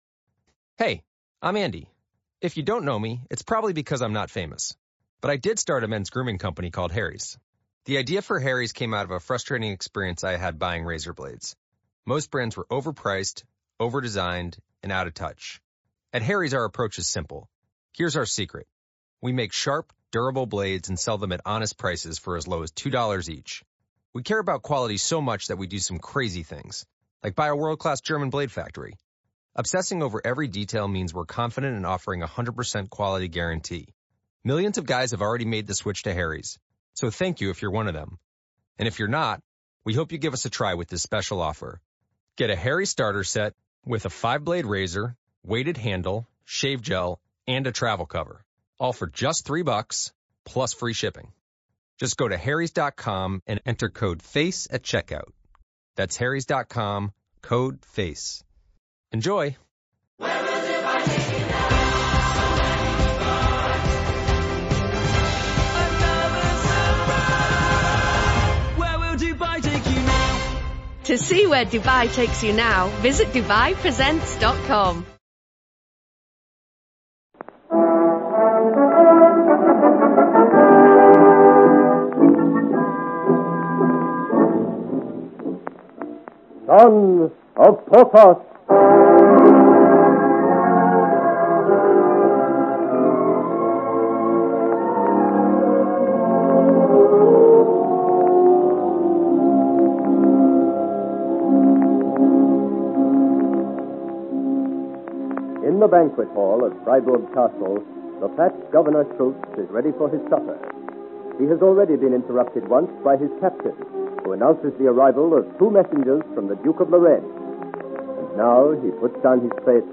Genre: Adventure, Romance, Drama